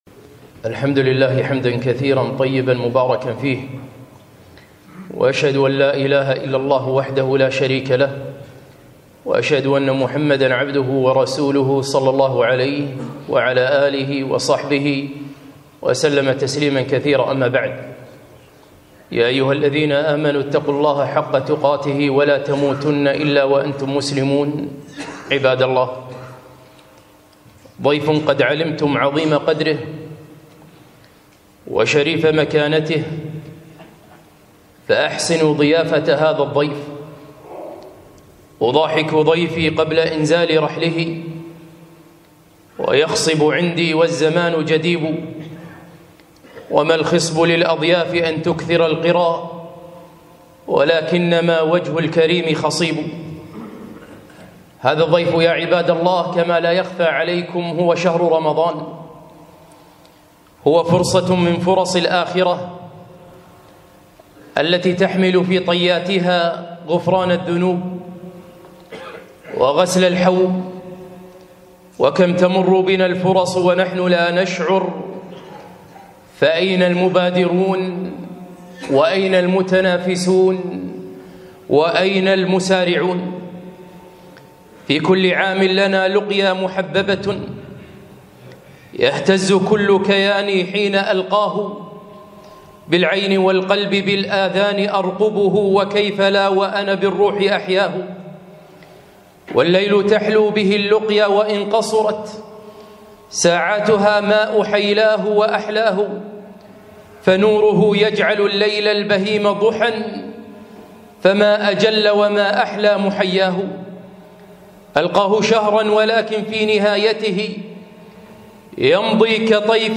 خطبة - اغتنم فرصة رمضان